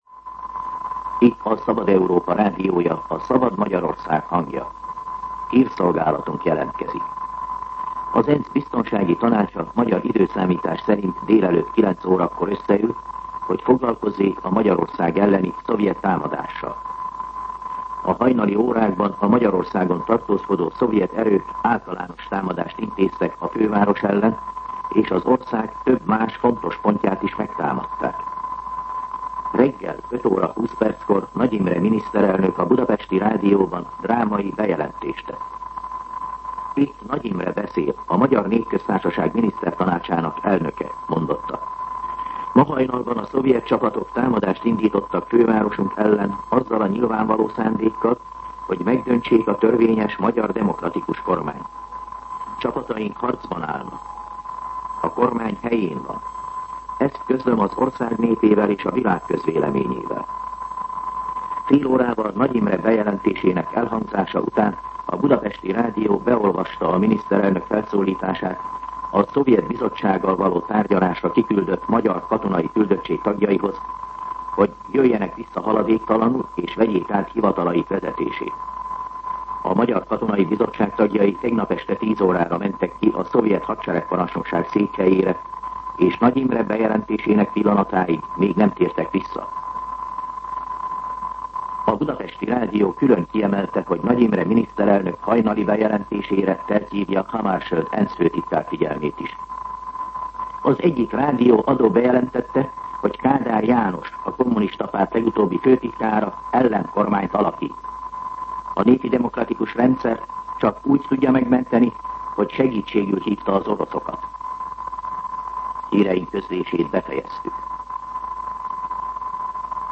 MűsorkategóriaHírszolgálat